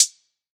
Closed Hats
SOUTHSIDE_hihat_20s.wav